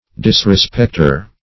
Disrespecter \Dis`re*spect"er\, n. One who disrespects.